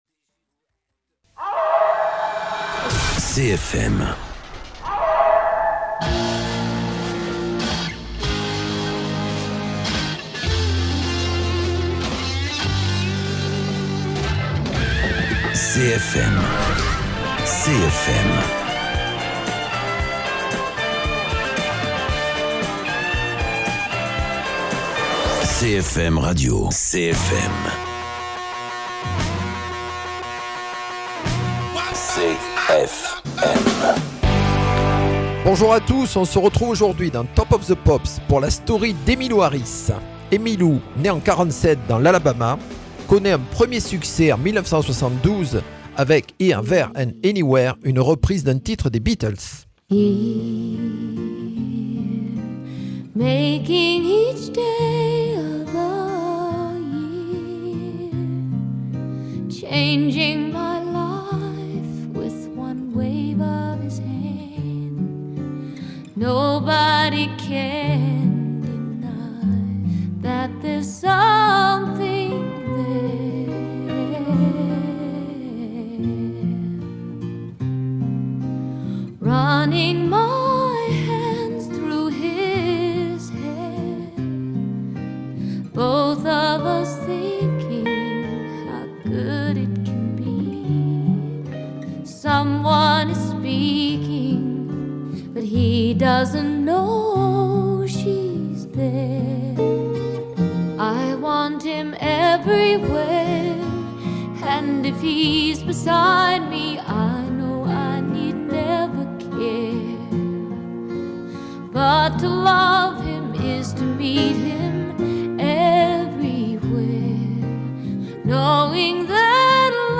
La chanteuse de country avec